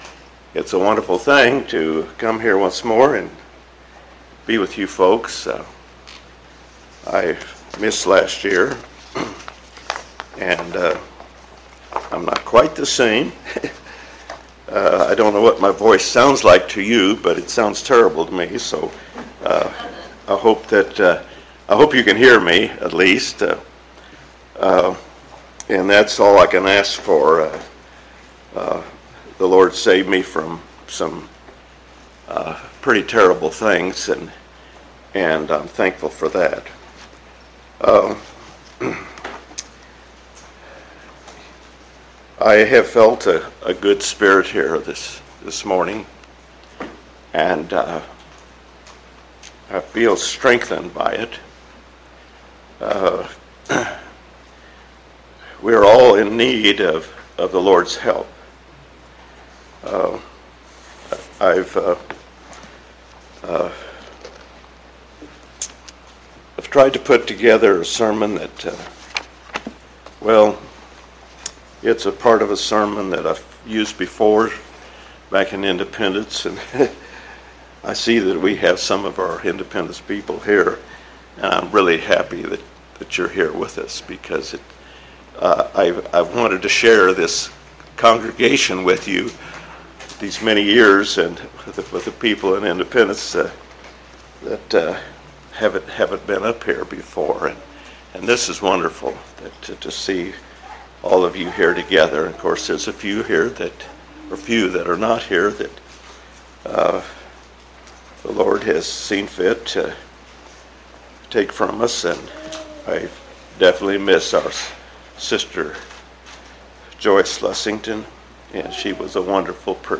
Michigan Reunion Event: Michigan Reunion